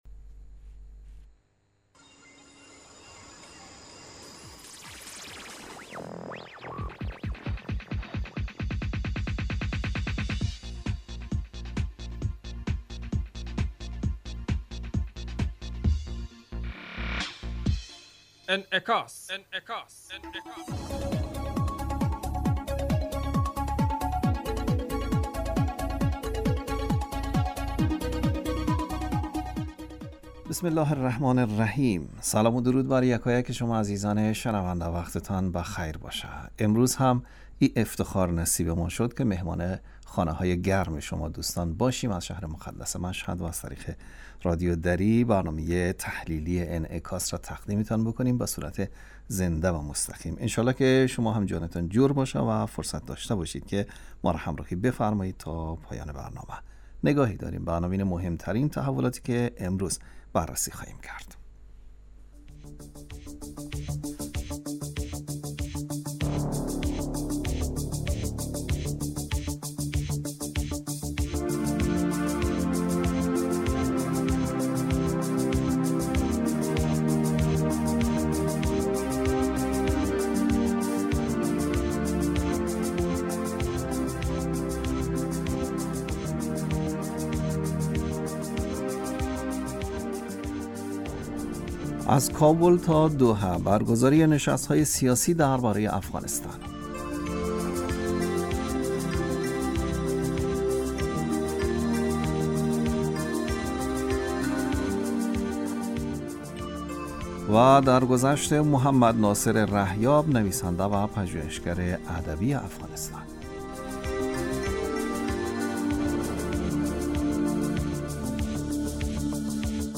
برنامه انعکاس به مدت 35 دقیقه هر روز در ساعت 06:50 بعد از ظهر (به وقت افغانستان) بصورت زنده پخش می شود.